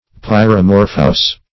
Search Result for " pyromorphous" : The Collaborative International Dictionary of English v.0.48: Pyromorphous \Pyr`o*mor"phous\, a. [Pyro- + -morphous.]
pyromorphous.mp3